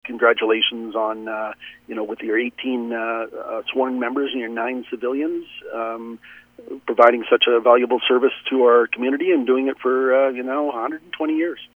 They received a commendation from city council last week and Chief Paul Burkart explains what it says.